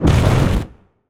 fireball_blast_projectile_spell_03.wav